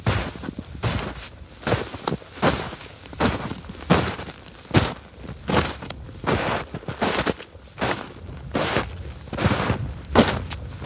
描述：雪在下脚踩着
标签： 散步 足迹 室外 紧缩